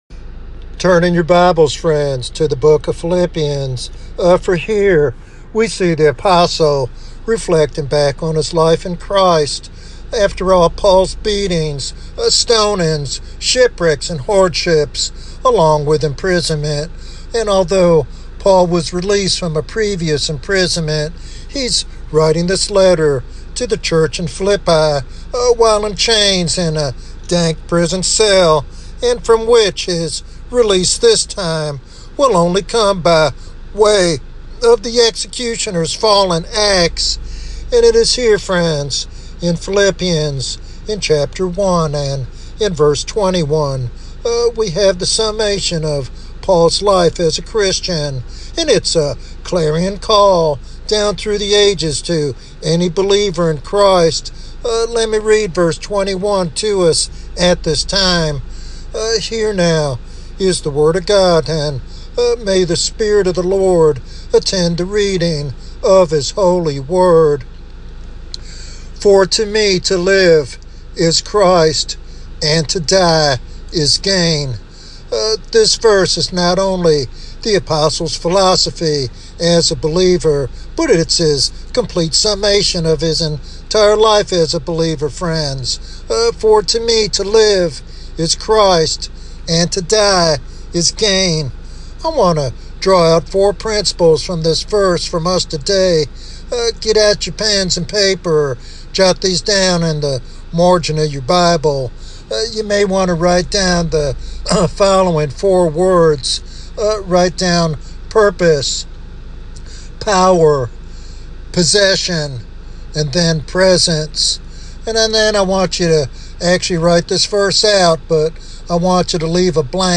In this expository sermon